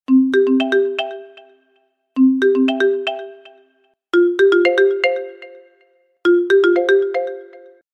Стандартные рингтоны